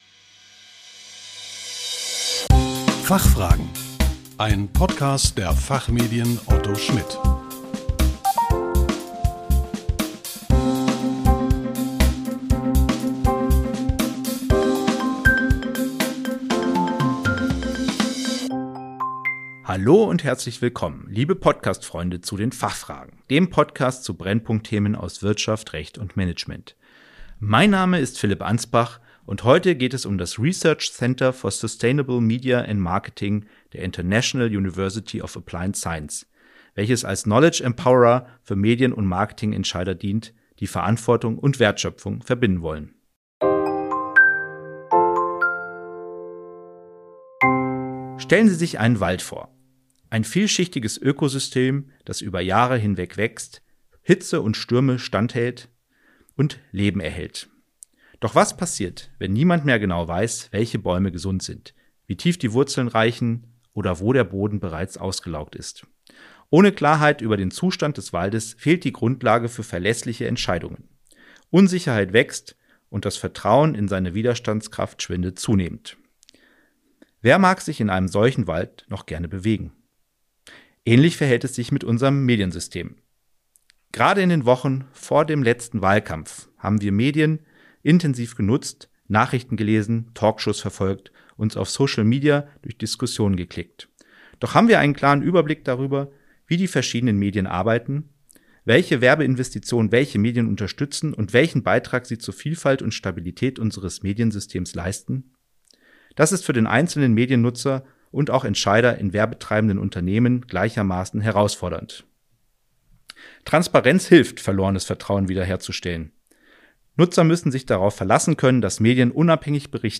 Im Podcast der Fachmedien Otto Schmidt stellen wir ausgewiesenen Expert:innen je 5 Fragen zu aktuellen Brennpunkt-Themen aus Wirtschaft, Recht und Management. Einfach und kurz erklärt.